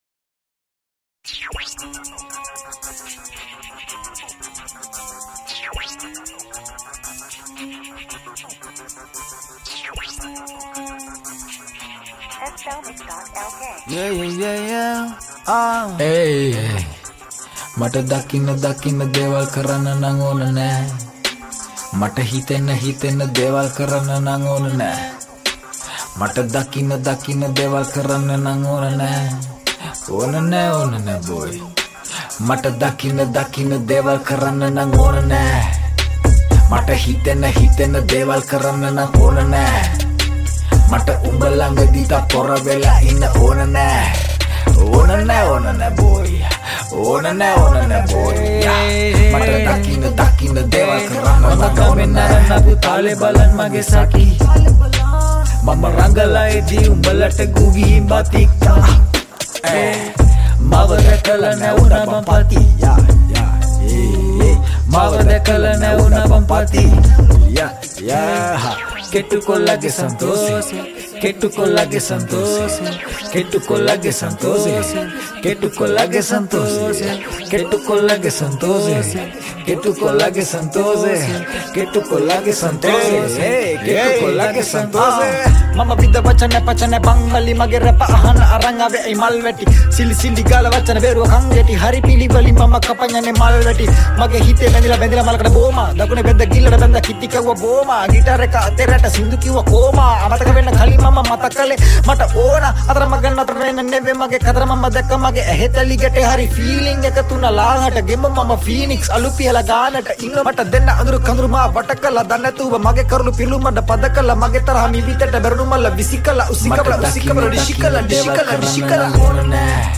Sri Lankan remix
Rap